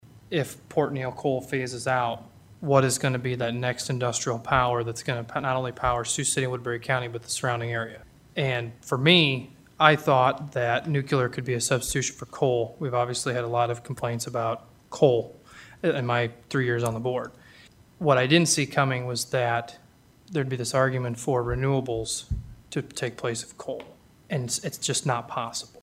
SUPERVISOR MARK NELSON SPOKE ABOUT HOW THERE ARE CONCERNS ABOUT LOSING MIDAMERICAN ENERGY’S LOCAL COAL FIRED ELECTRIC GENERATING PLANTS, SO THAT’S A REASON REZONING FOR NUCLEAR IS BEING CONSIDERED: